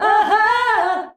AHAAH B.wav